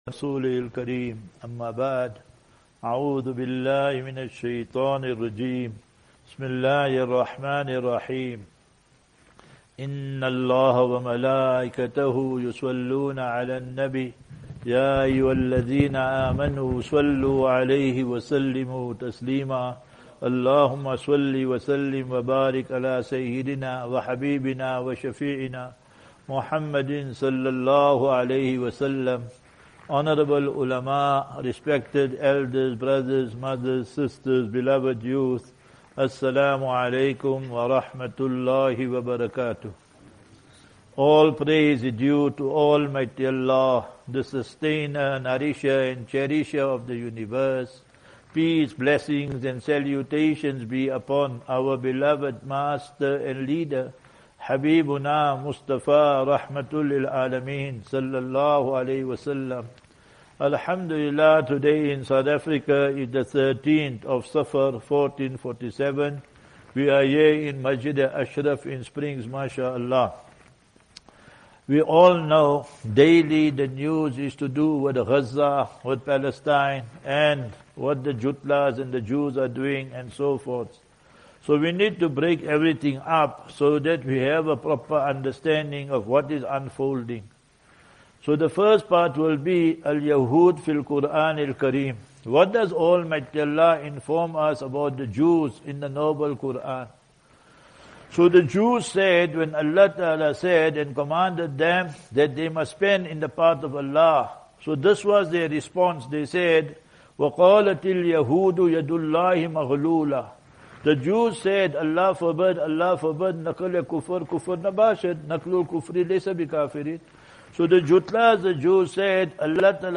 8 Aug 08 August 2025 - Jum'uah Lecture at Masjidul Ashraf (Bakerton) Springs